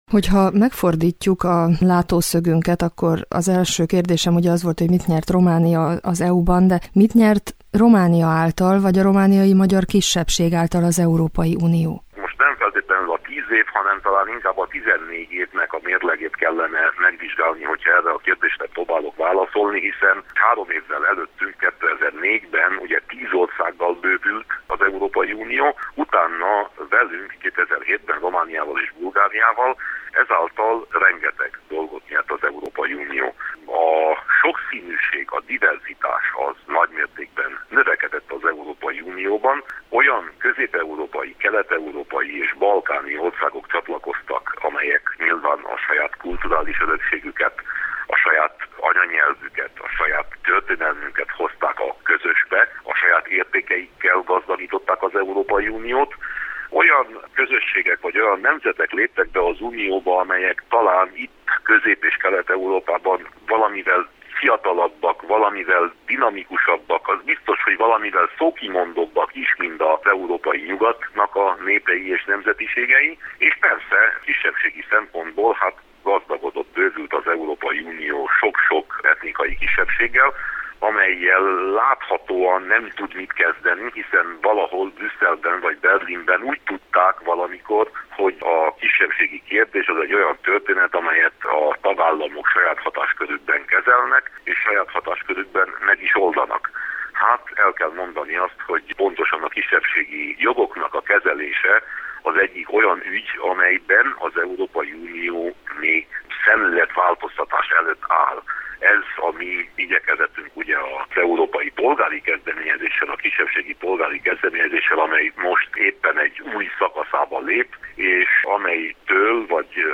Európa-napi beszélgetés Winkler Gyulával – Bukaresti Rádió